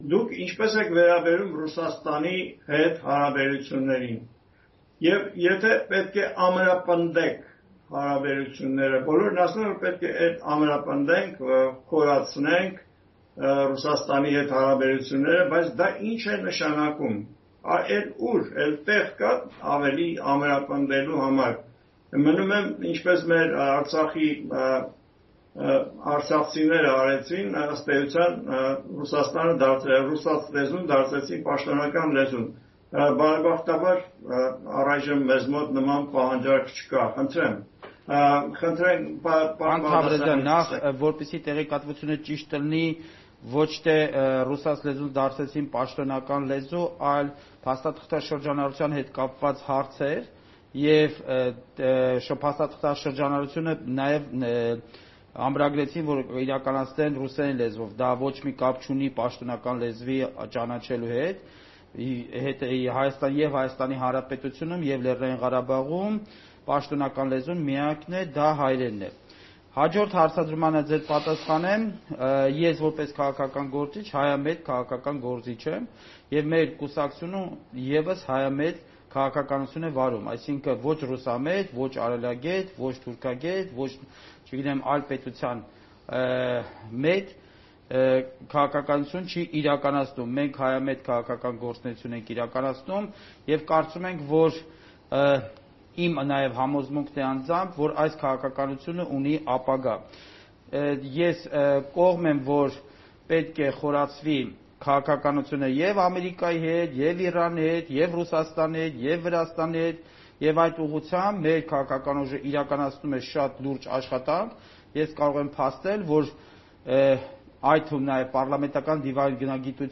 Ռուսաստանի հետ հարաբերություններ․ բանավեճ «Ազատության» տաղավարում